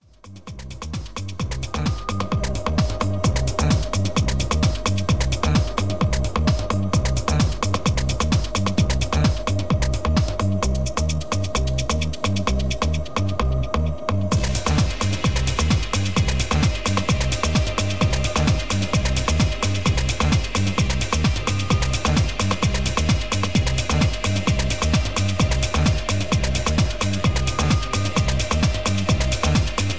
It's a techhouse story told here...